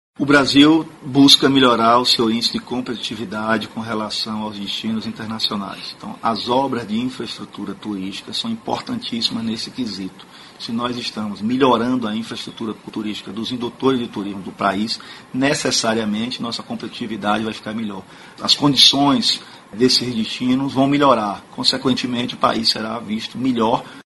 aqui para ouvir declaração de Fábio Mota, secretário nacional de Programas de Desenvolvimento do Turismo, sobre a importância da realização de obras de infraestrutura turística.